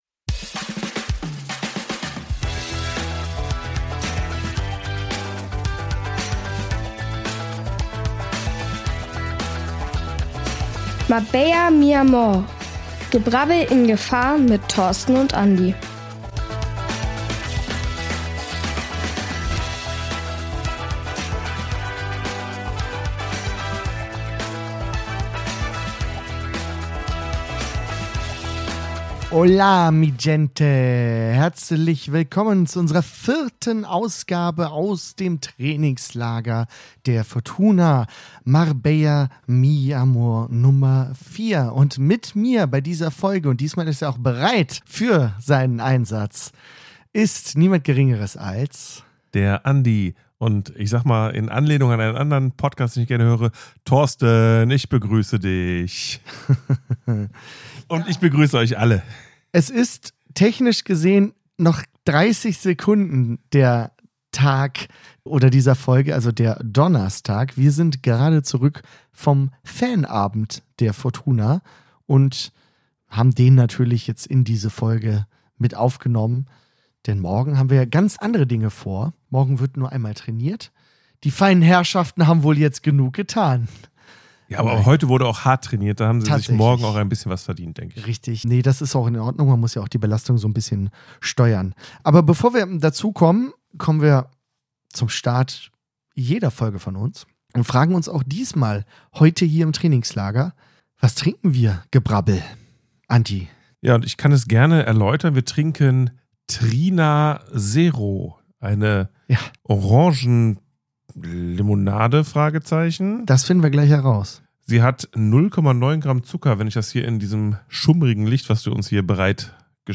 Neben zwei Trainingseinheiten stand heute vor allem der Fanabend der Fortuna auf dem Programm. Da konnten wir ein sehr nettes, längeres Gespräch mit Moritz Heyer führen.